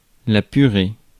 Ääntäminen
France: IPA: [py.ʁe]